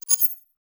Simple Digital Connection 10.wav